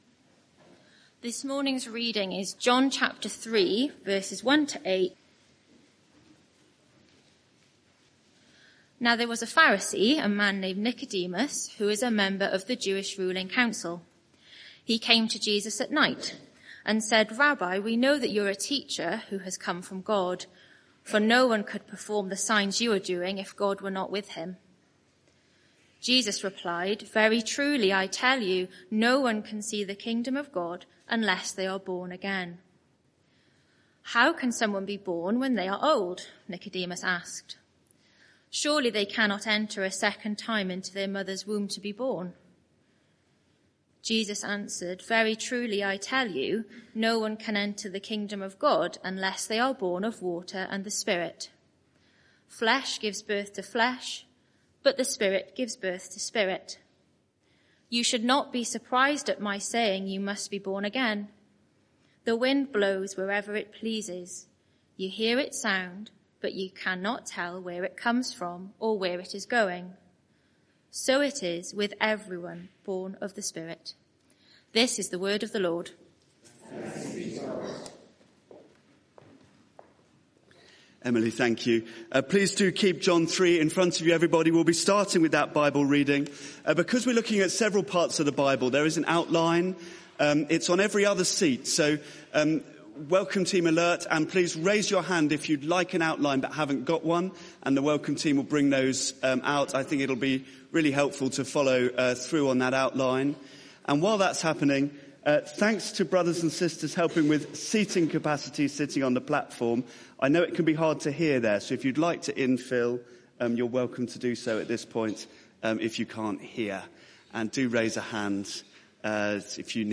Sermon (audio) Search the media library There are recordings here going back several years.